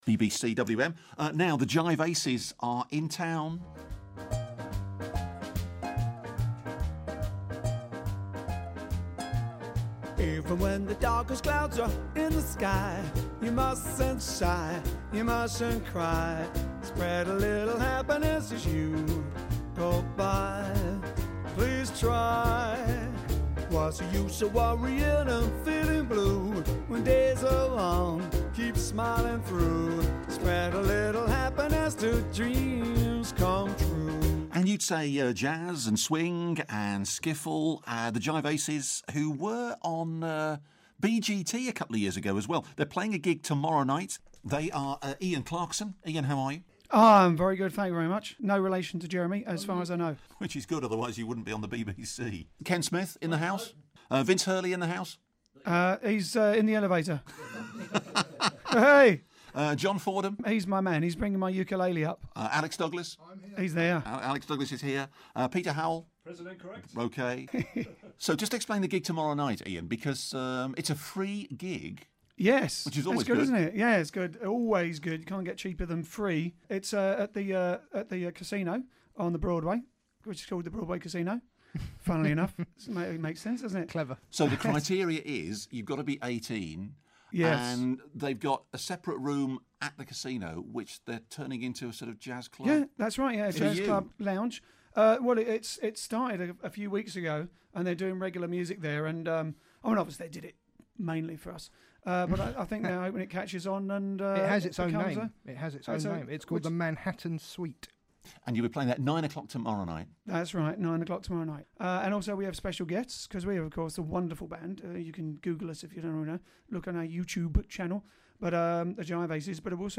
The Jive Aces are a six-piece jive and swing band.